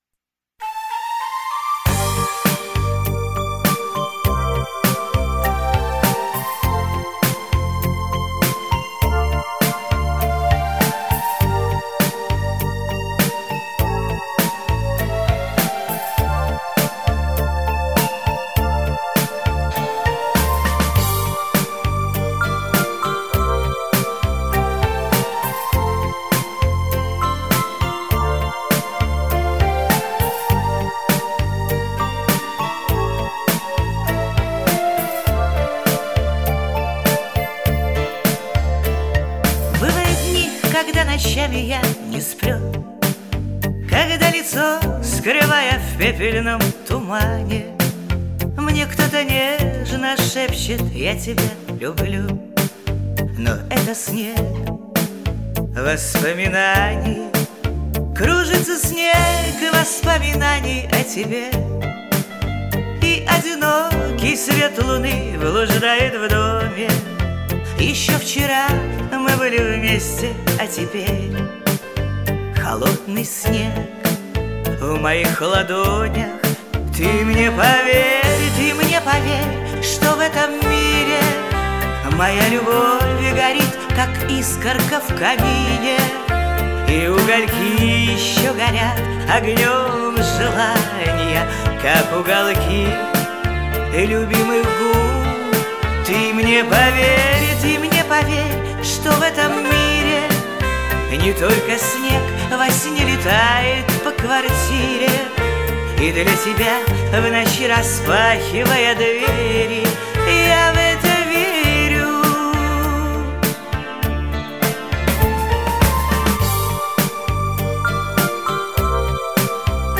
явно выигрывает в качестве записи